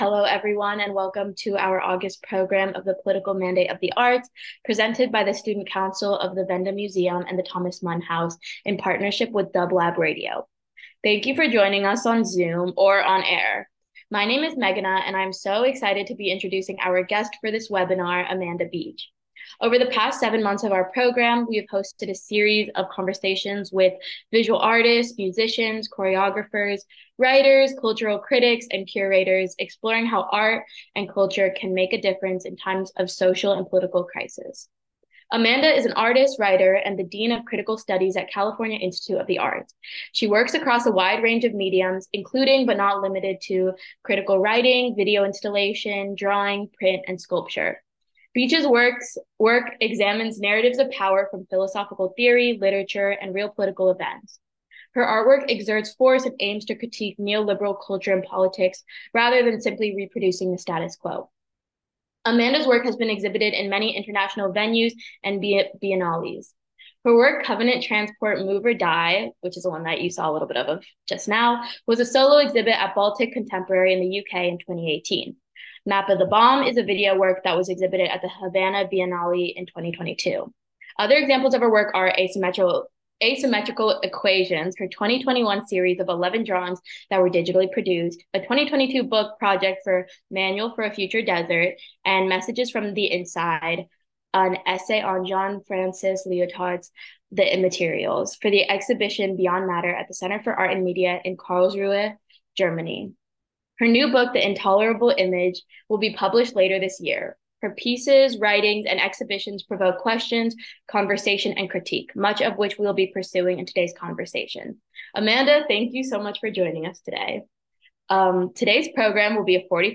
Interview Talk Show
The program takes place live every fourth Wednesday of the month 4.15pm PST, where you can join the Q&A. More details HERE .